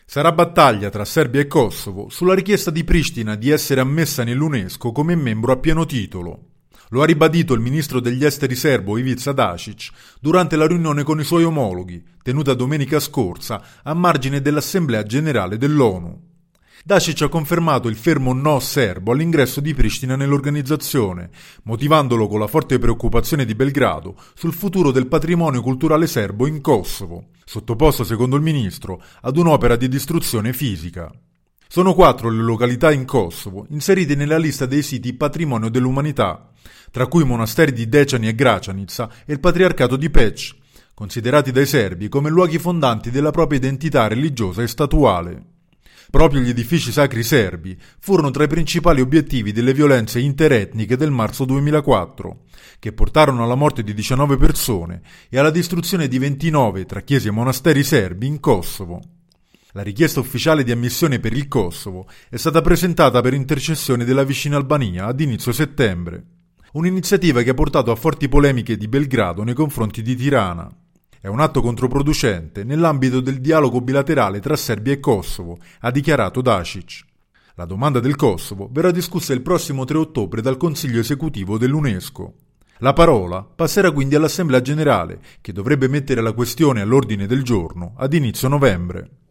Entra nel vivo la sfida tra Belgrado e Pristina sulla richiesta di ingresso all’UNESCO del Kosovo, che verrà discussa nelle prossime settimane. Il servizio